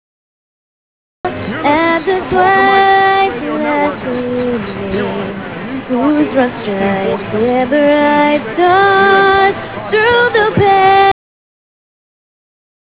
This page contains DX Clips from the 2008 DX season!